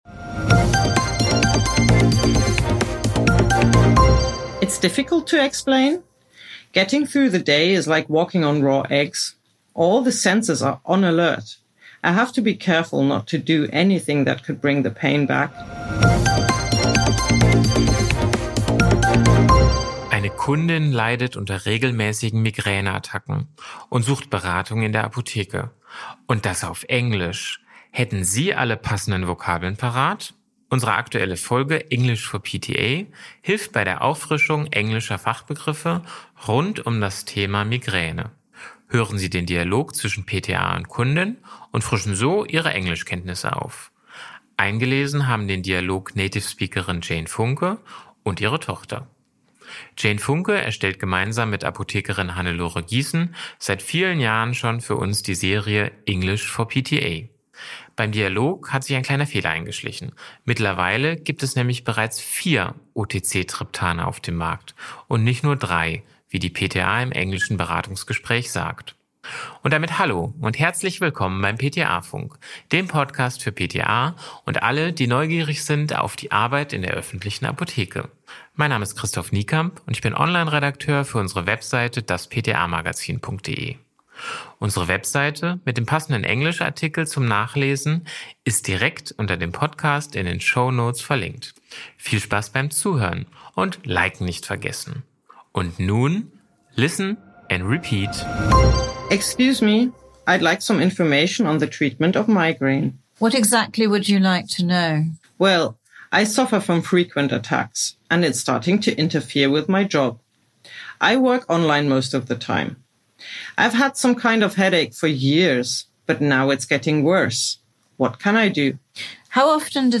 Native Speakerin